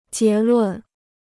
结论 (jié lùn): conclusion; verdict.